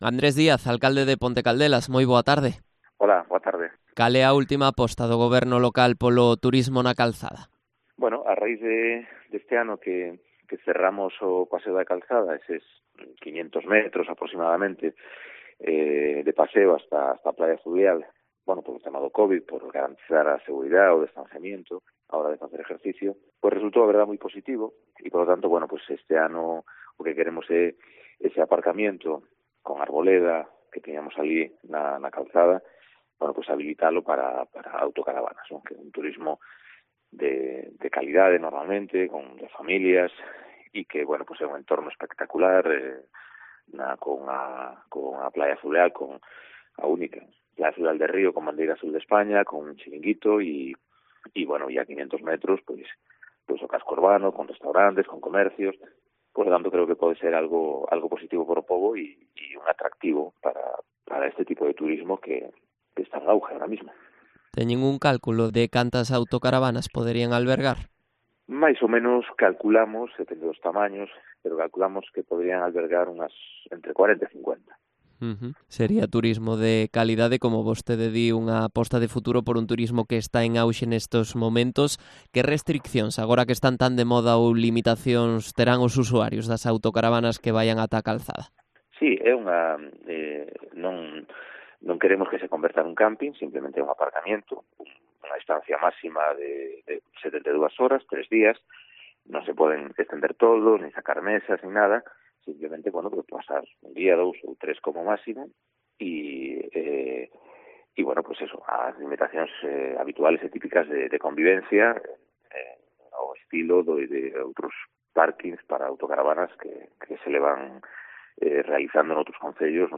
Entrevista a Andrés Díaz, alcalde de Ponte Caldelas